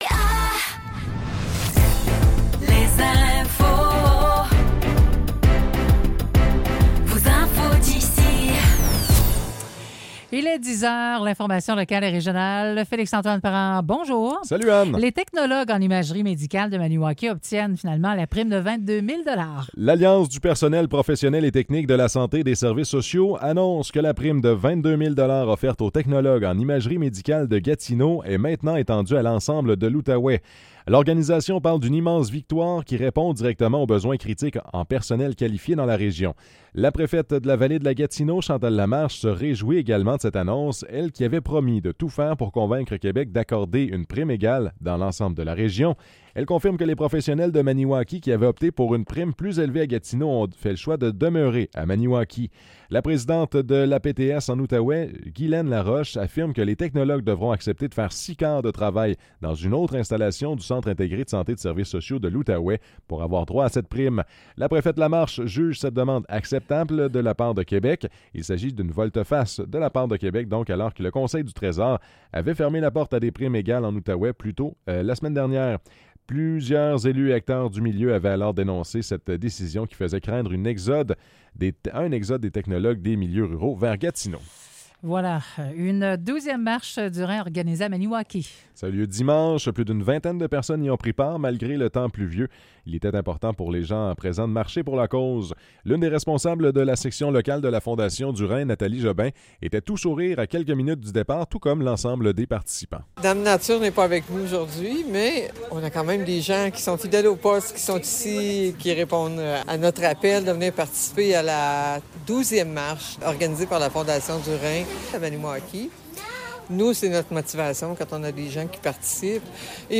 Nouvelles locales - 9 septembre 2024 - 10 h